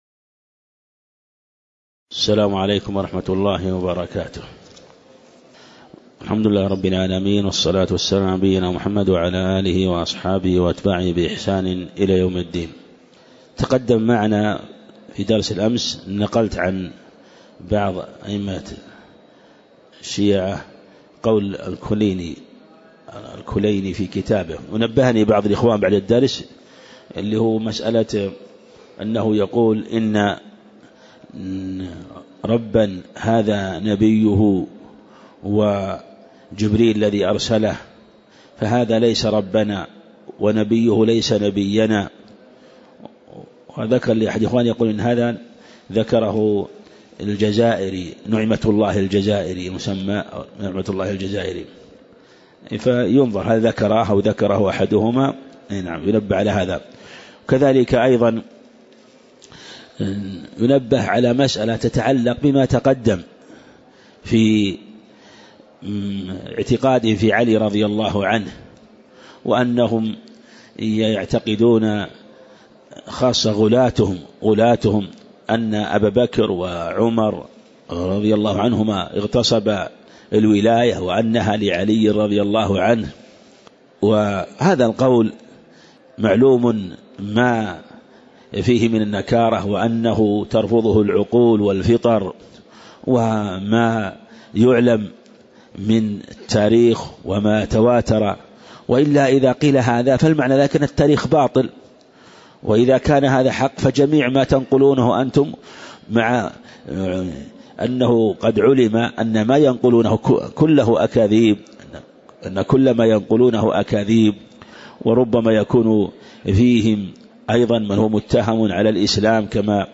تاريخ النشر ٢٢ شوال ١٤٣٨ هـ المكان: المسجد النبوي الشيخ